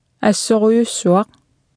Martha tassaavoq kalaallisut qarasaasiakkut atuffassissut.
MP3-tut nipinngortiillugu aaneqarsinnavoq.